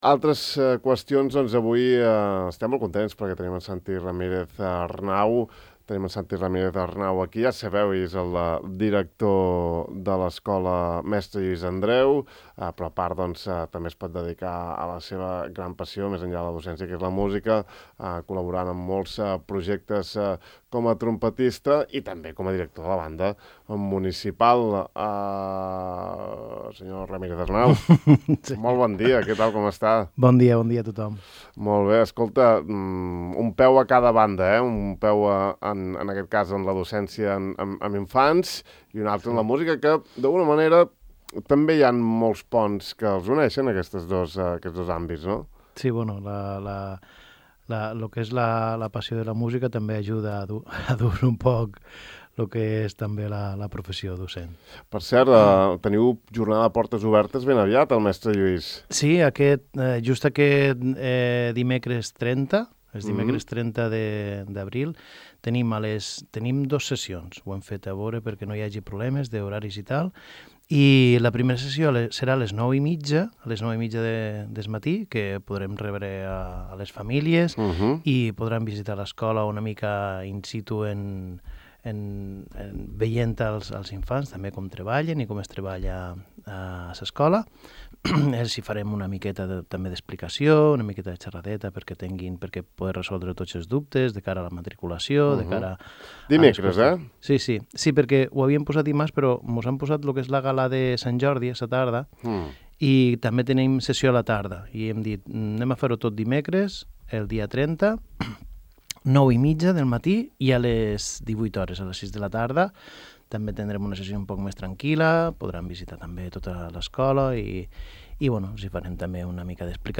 Podeu recuperar aquesta estona de ràdio i música clàssica aquí: